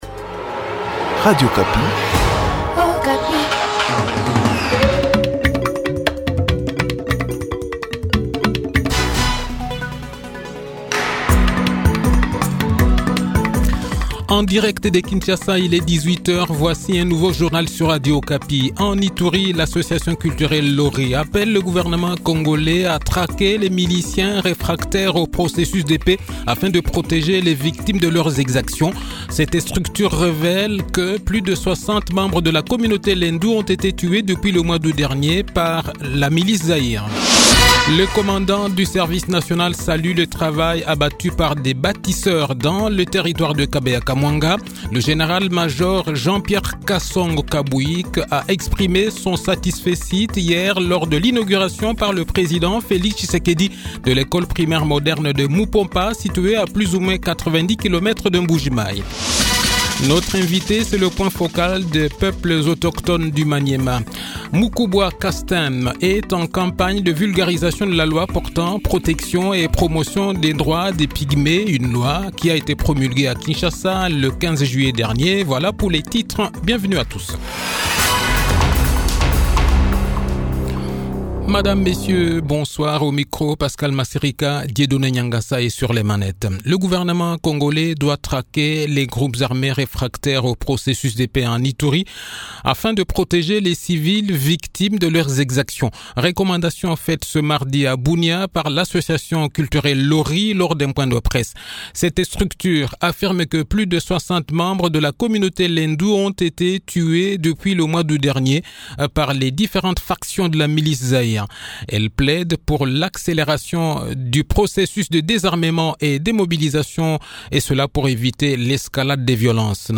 Le journal de 18 h, 3 janvier 2023